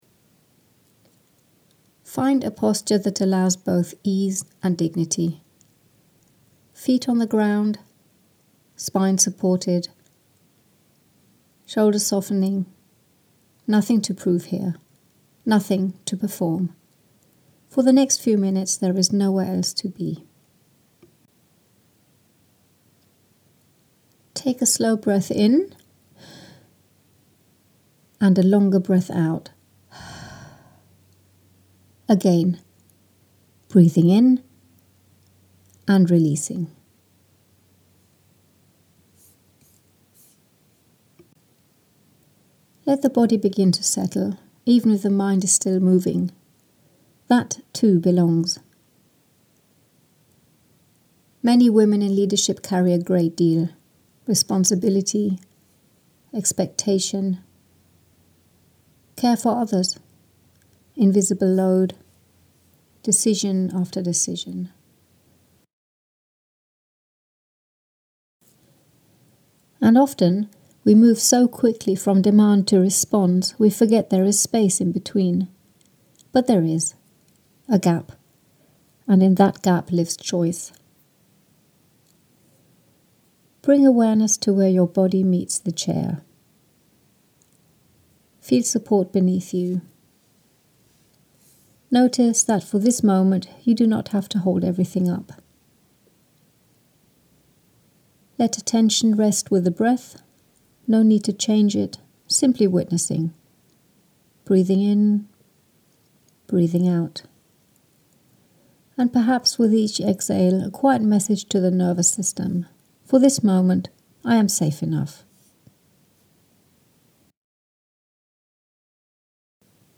Geführte Impulse